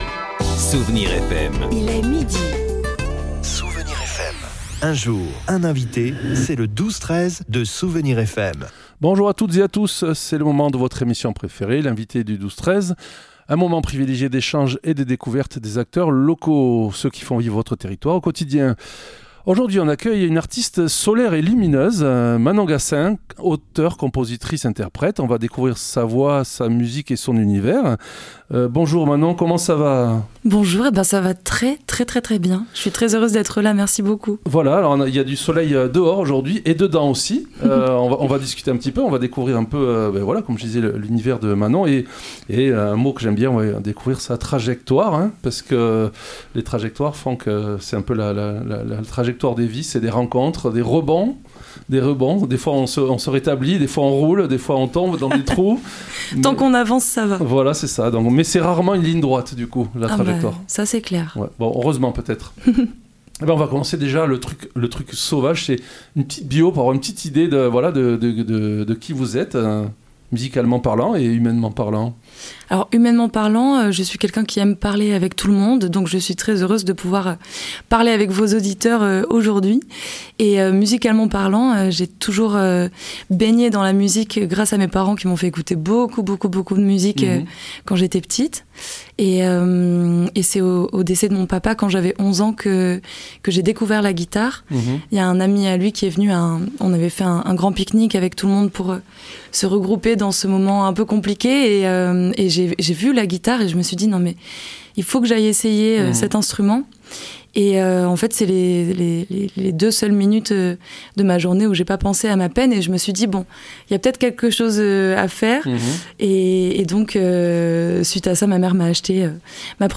Un EP est actuellement en préparation, et nous avons diffusé un single pour vous faire patienter un peu.